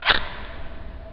lighter flick segment slowed down, turnt up, turnt down again
experimental fx lighter-flick logic-pro MTC500-M002-s14 sound-enigma sound effect free sound royalty free Memes